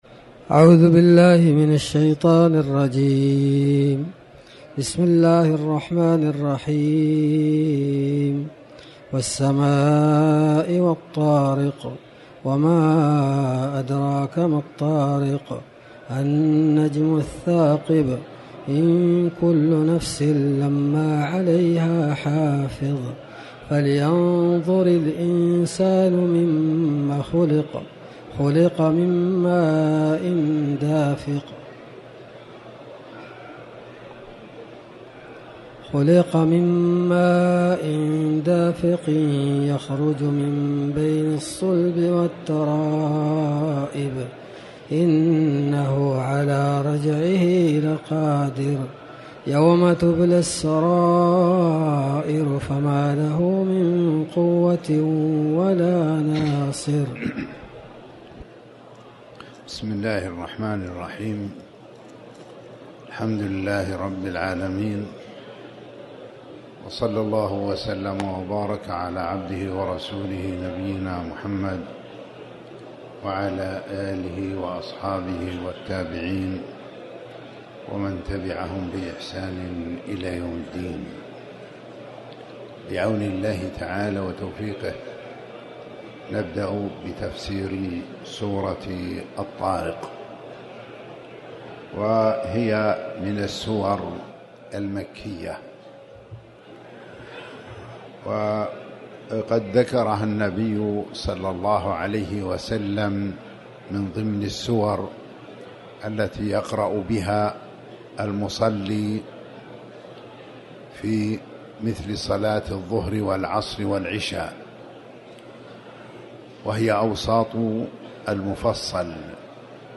تاريخ النشر ٢٢ رمضان ١٤٤٠ هـ المكان: المسجد الحرام الشيخ